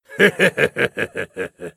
laughter_01